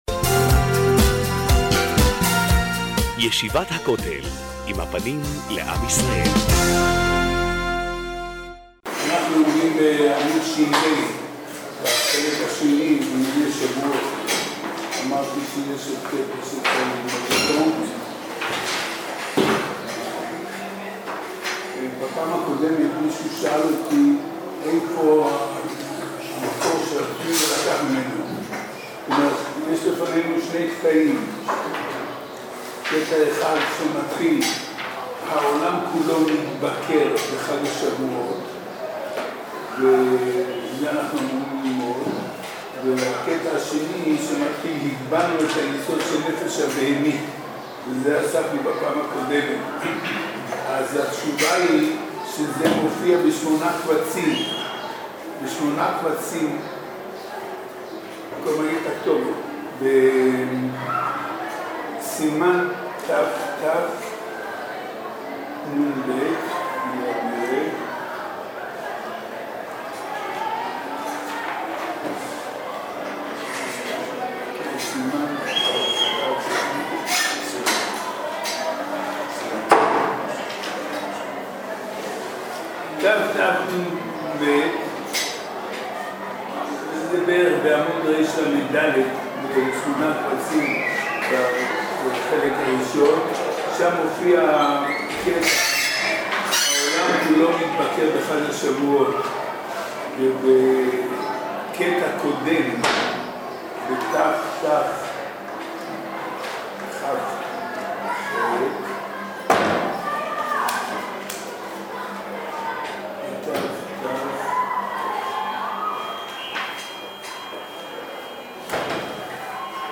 תשע"ד להאזנה לשיעור